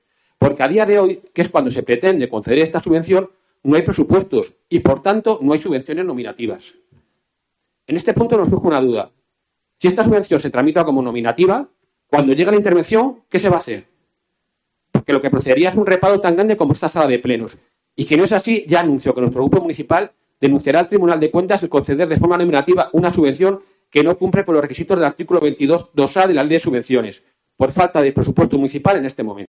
Fernando Contreras, concejal PSOE. Sobre la subvención a la Junta de Semana Santa